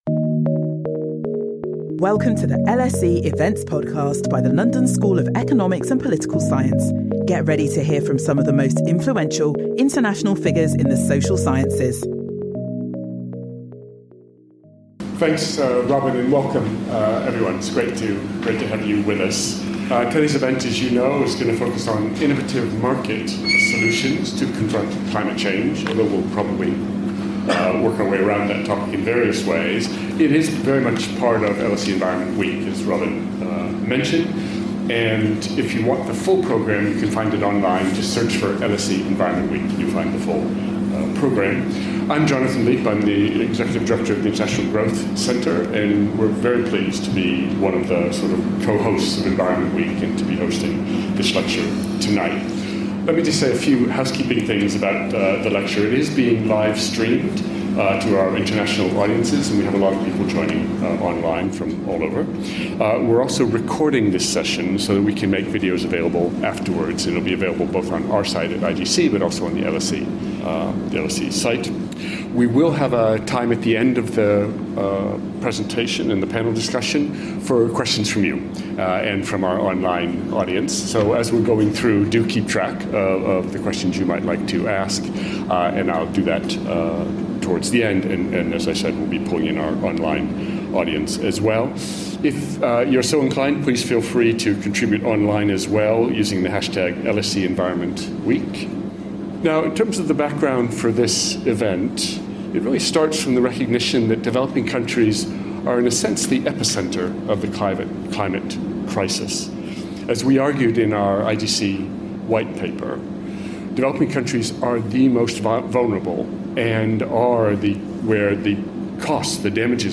This talk brings together insights from policymakers, international organisation representatives, the private sector, and academia to explore how markets, such as for voluntary carbon credits, can support the advancement of sustainable development goals.